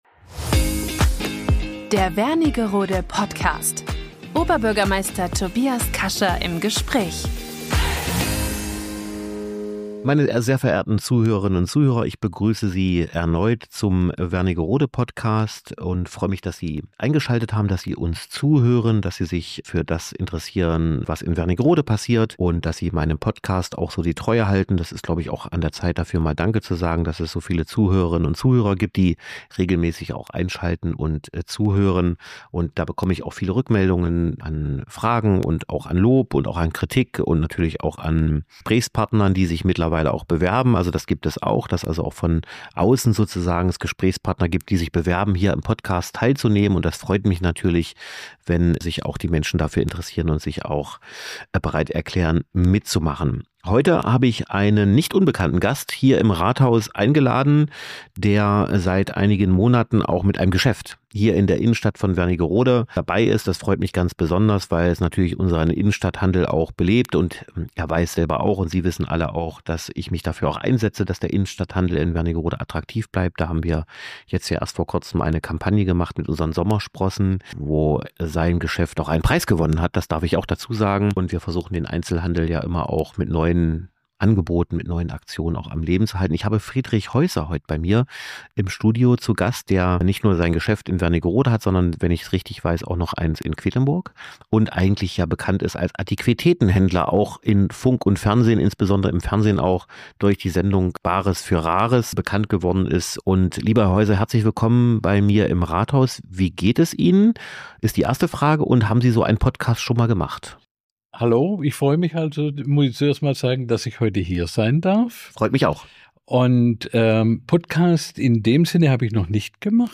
Ein offenes Gespräch über Leidenschaft, Heimatverbundenheit und den Netzwerken-Gedanken in einer Stadt mit Charme.